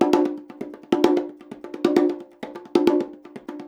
130BONGO 11.wav